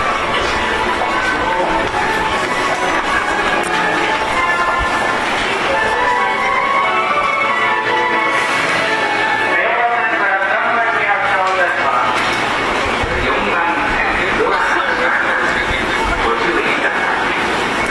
発車4音色a 曲は普通です。